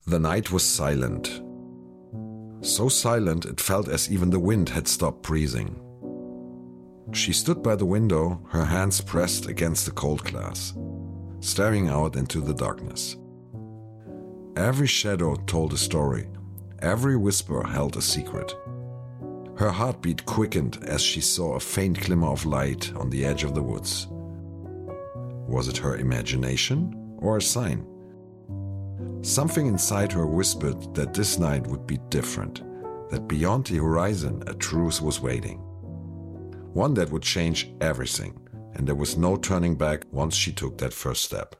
Male
I work both, in German and in English with accent. My voice ranges from warm and deep to calm and engaging, making it appropriate for a variety of projects.
Audiobooks
English Audiobook 2